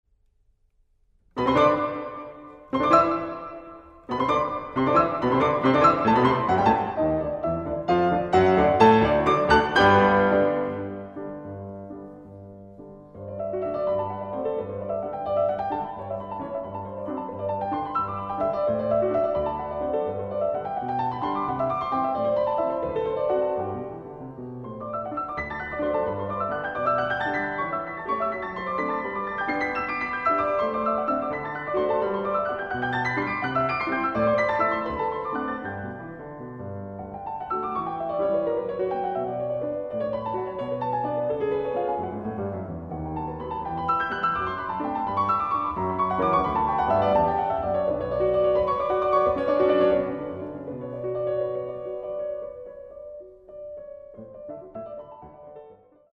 for piano 4 hands